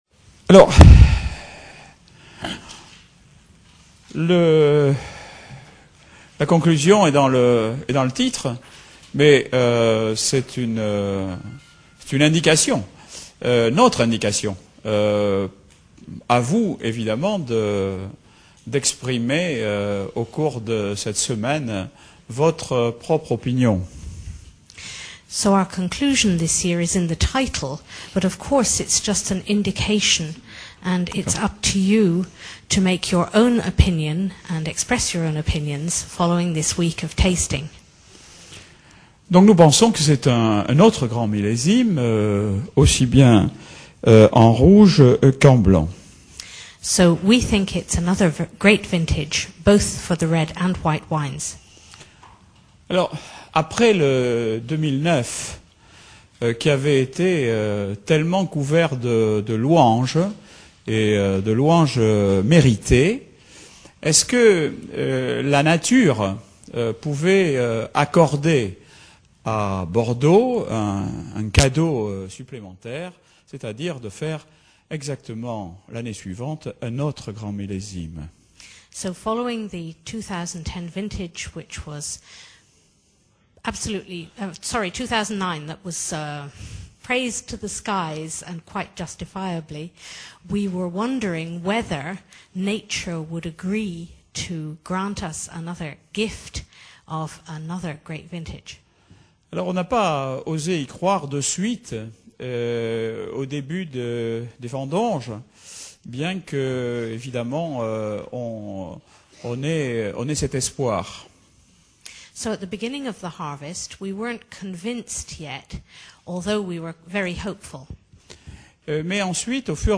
Conférence bilingue français-anglais